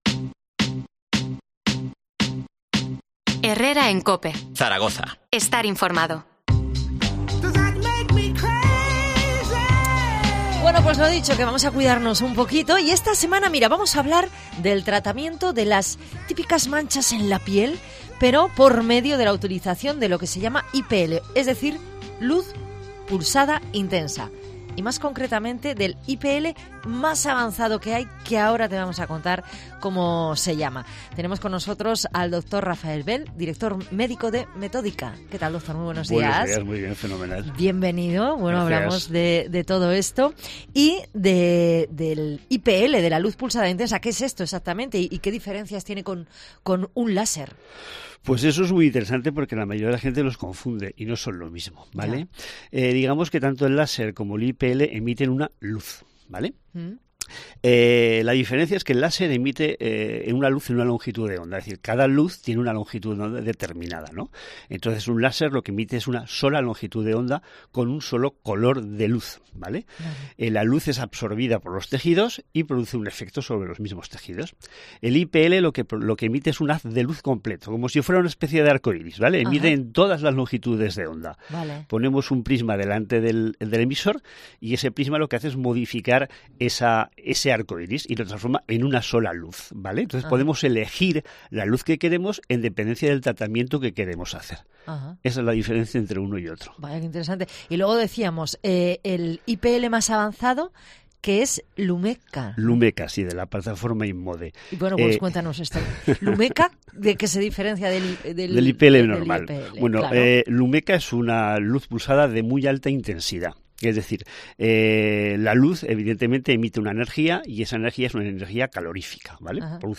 Zaragoza